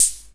Closed Hats
Wu-RZA-Hat 37.wav